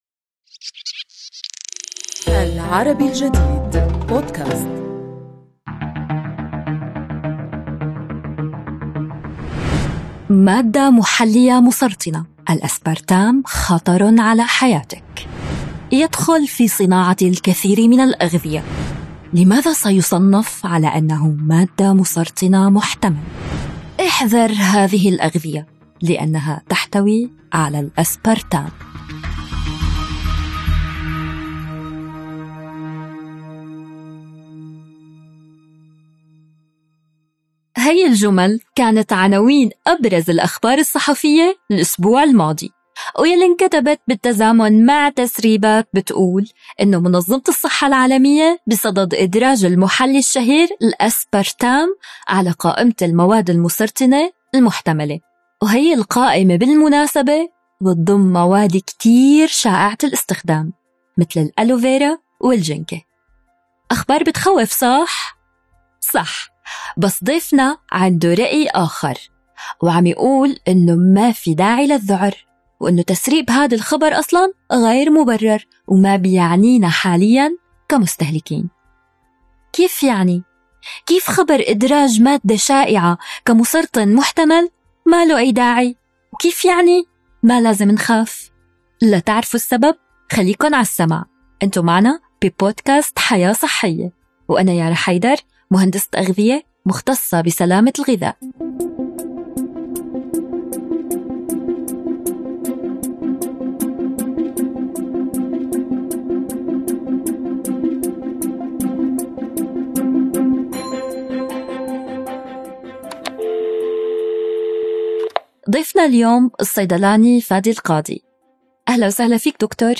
نستضيف في هذه الحلقة الصيدلاني